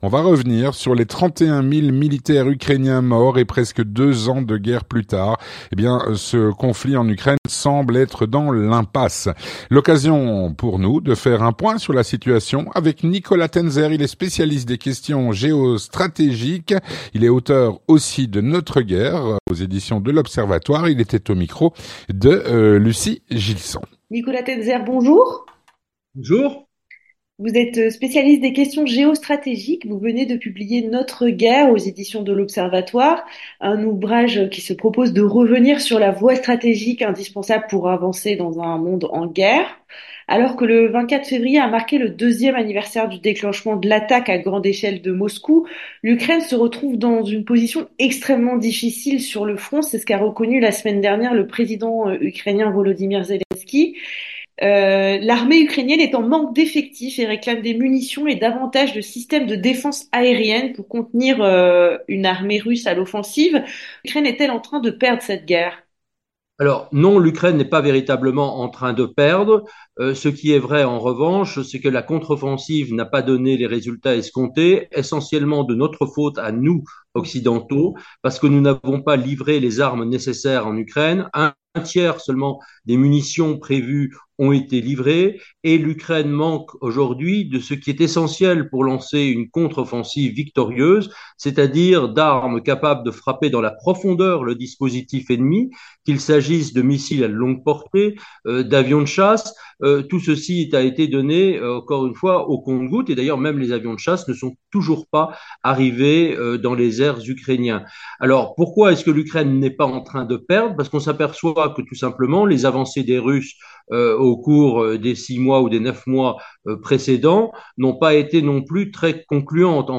L'entretien du 18H - Après 2 ans de guerre, le conflit en Ukraine semble être dans l’impasse.